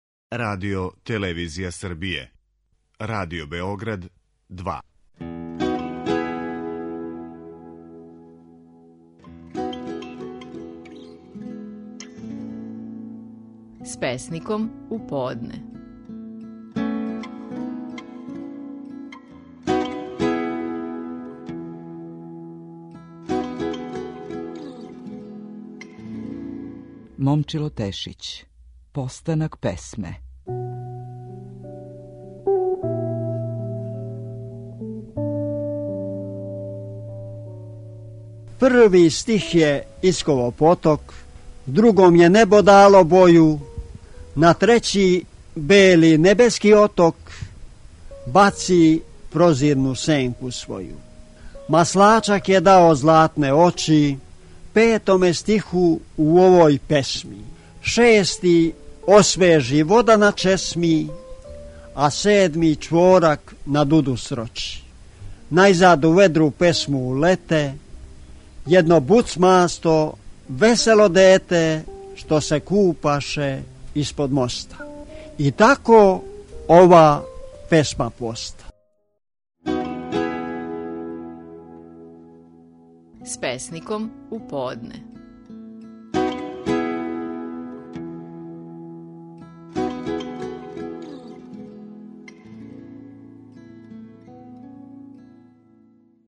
Стихови наших најпознатијих песника, у интерпретацији аутора.
Момчило Тешић говори песму „Постанак песме".